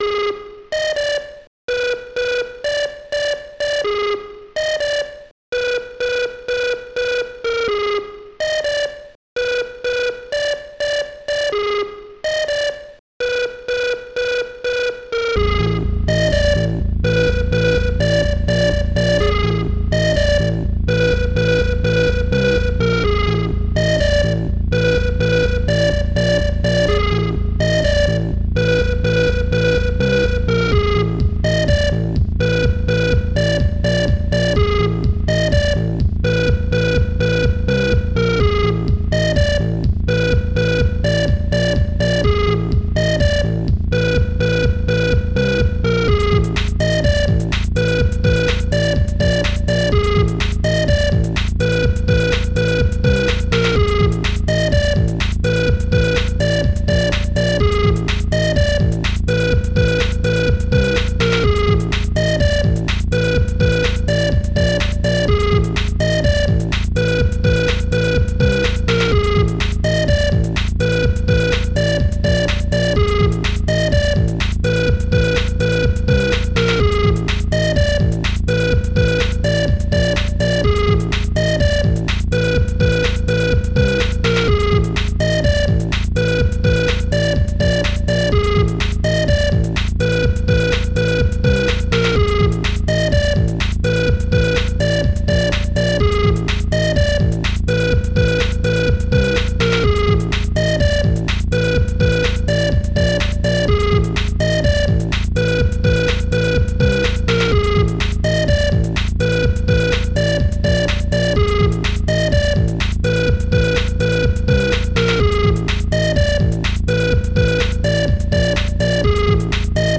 Protracker and family
basedrum
claps
basesynth
beep
hihat